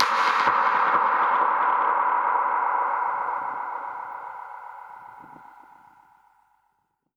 Index of /musicradar/dub-percussion-samples/134bpm
DPFX_PercHit_A_134-05.wav